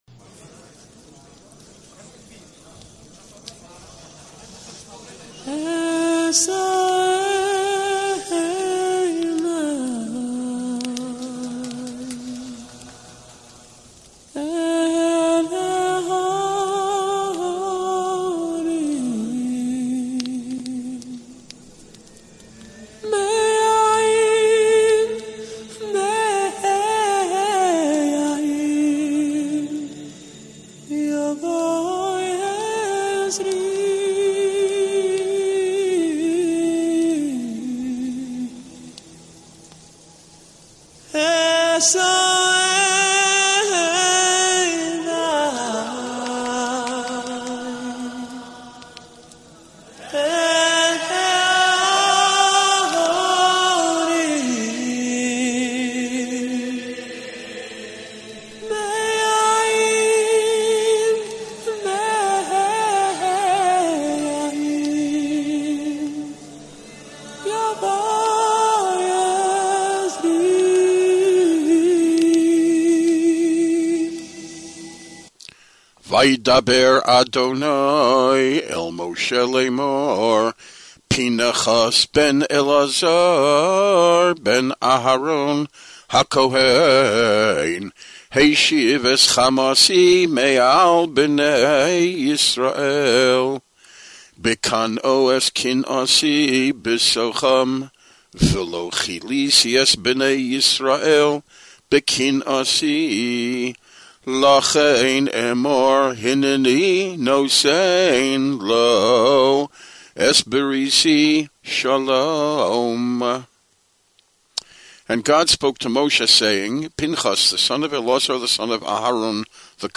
With some nice a capella music by Be